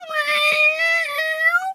cat_2_meow_long_02.wav